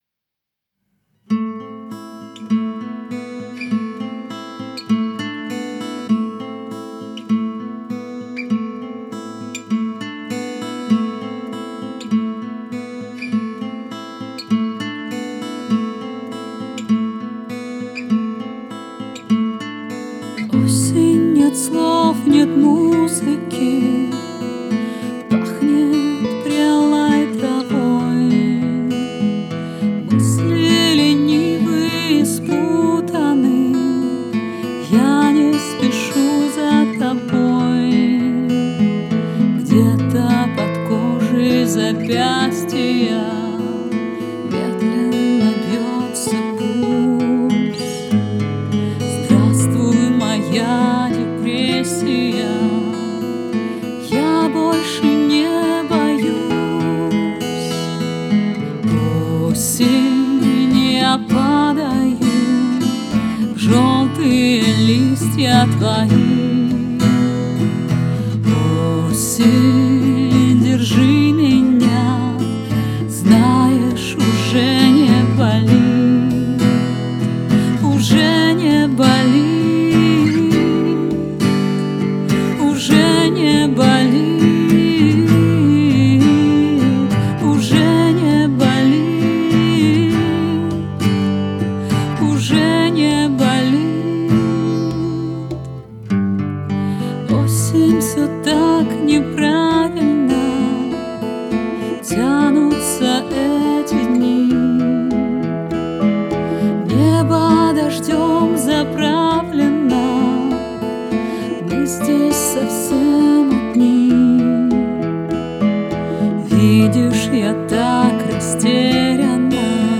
• Жанр: Рок, Русская музыка, Русский рок